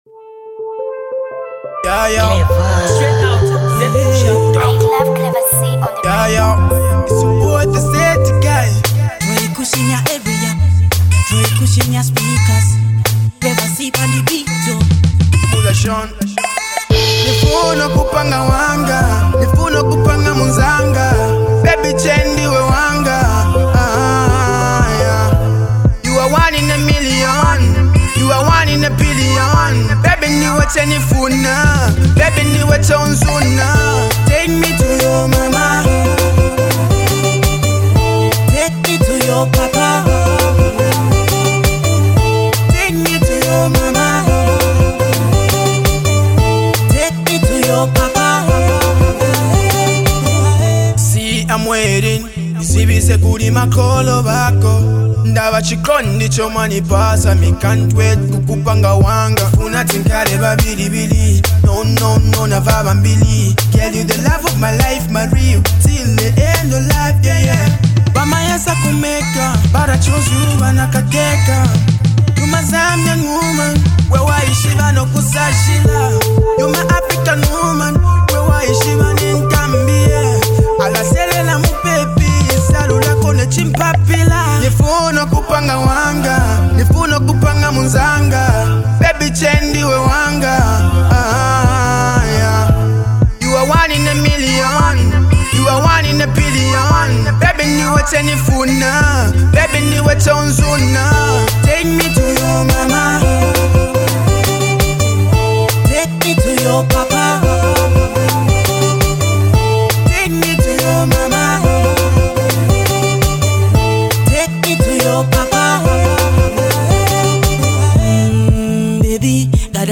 Afro/R&B